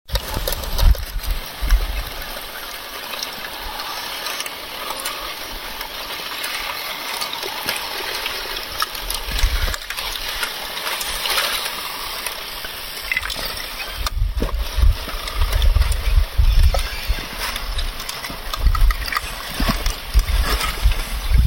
The ice on Son Kul lake, Kyrgyzstan melting with spring coming.